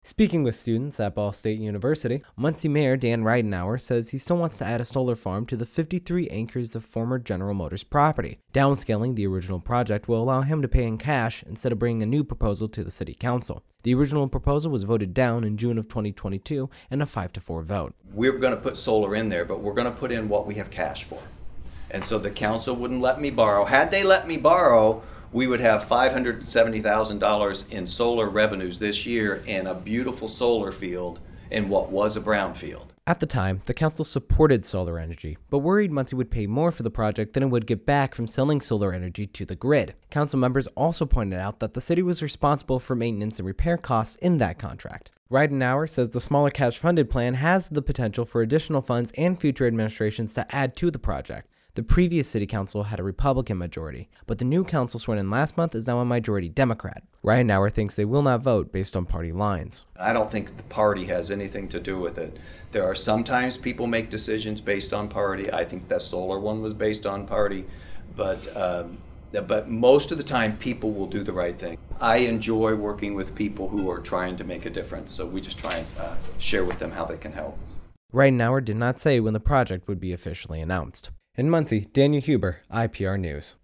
Speaking to journalism students at Ball State University, Muncie Mayor Dan Ridenour says he still wants to add a solar farm to the 53 acres of former General Motors property.